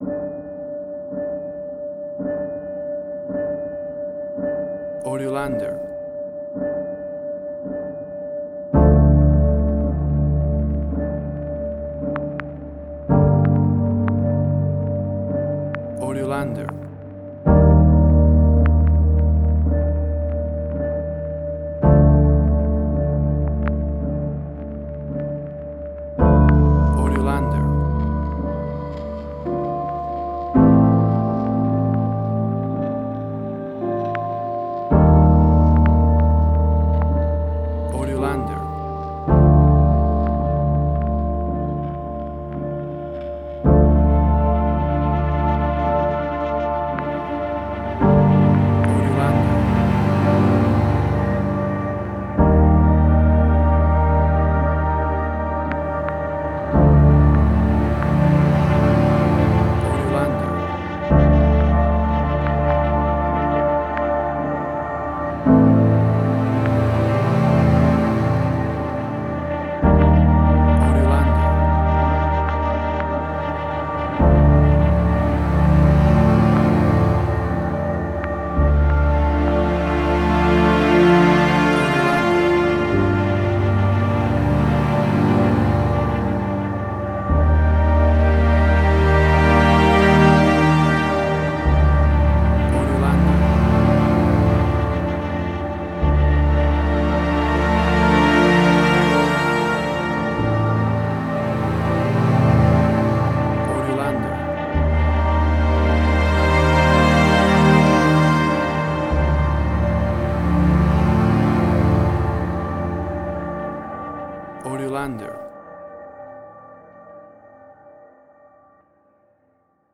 WAV Sample Rate: 16-Bit stereo, 44.1 kHz
Tempo (BPM): 55